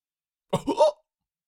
Cartoon Lion, Voice, Hiccup 2 Sound Effect Download | Gfx Sounds
Cartoon-lion-voice-hiccup-2.mp3